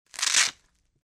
Touch Fastener Rip Sound
household